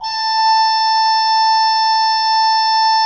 Index of /90_sSampleCDs/Propeller Island - Cathedral Organ/Partition L/HOLZGEDKT MR